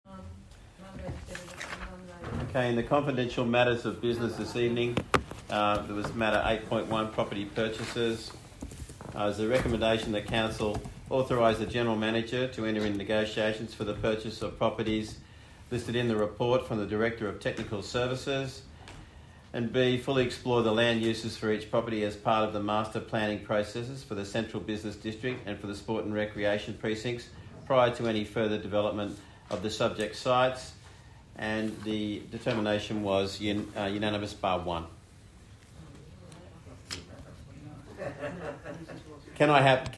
18 July 2023 Ordinary Meeting